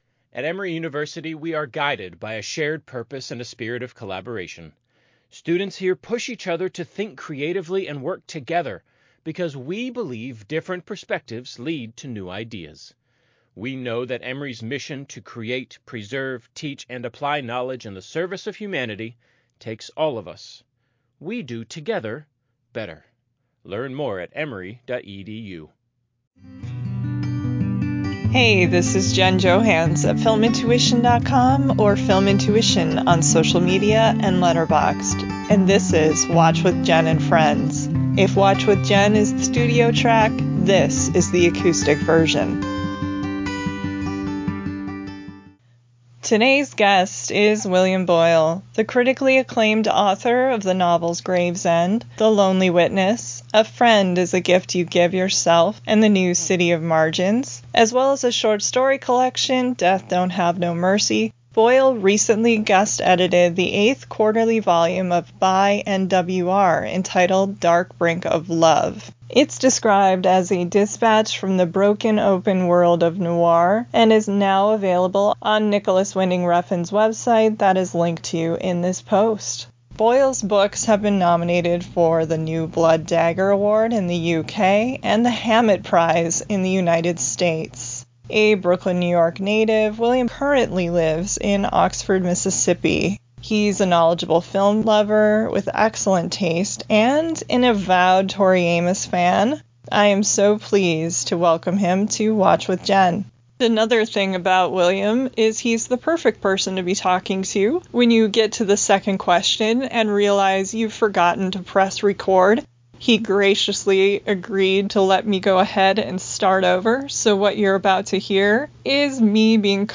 Theme Music: Solo Acoustic Guitar